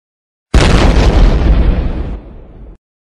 MKmwDG2hHsE_Sonido-De-Explosion-eCwGnS19svo.mp3